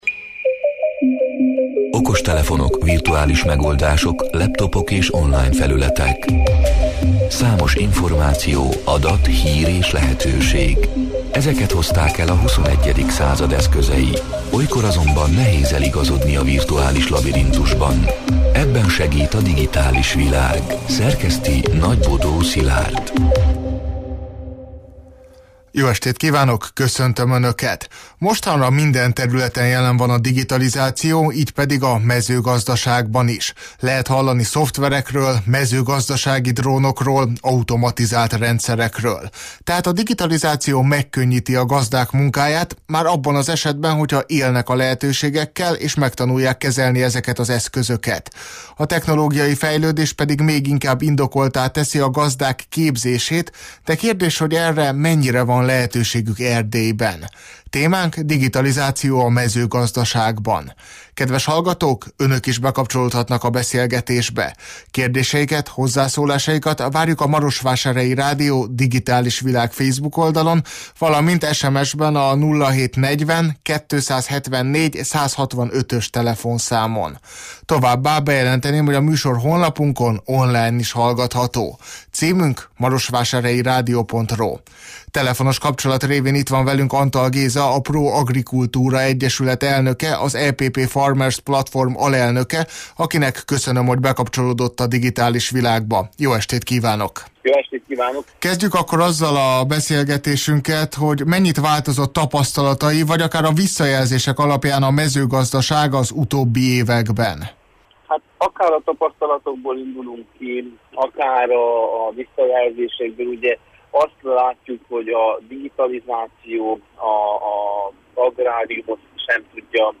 A Marosvásárhelyi Rádió Digitális Világ (elhangzott: 2025. október 7-én, kedden este nyolc órától élőben) c. műsorának hanganyaga: